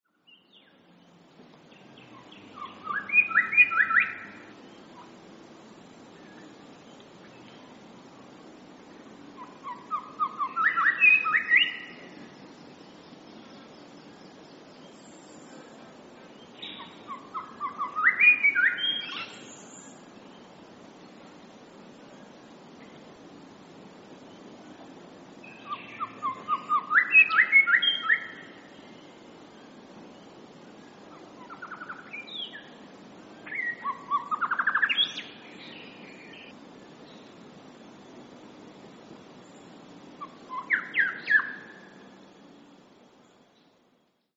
Grey Shrikethrush - Colluricincla harmonica
Voice: varied melodious trills and chortles, clear ringing call.
Call 1: melodious song
Grey_Shrikethr_song.mp3